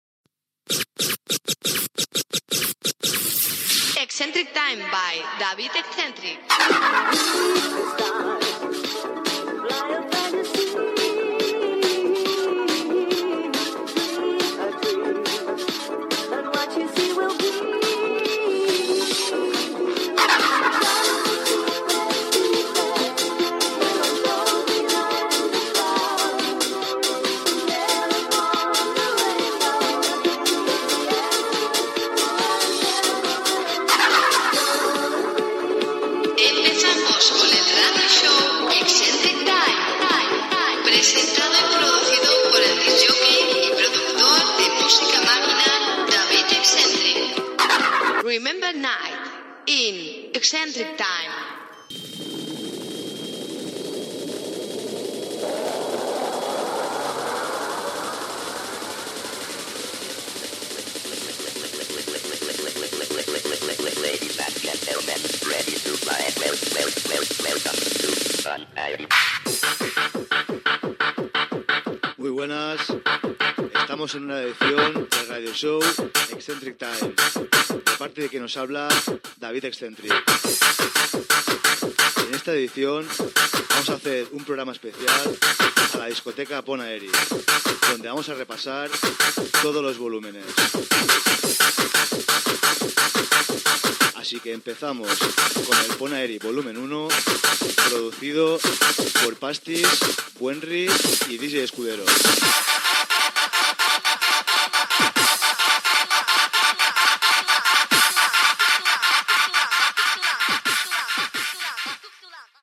Careta del programa, presentació del programa dedicat a la discoteca Pont Aeri
Musical
FM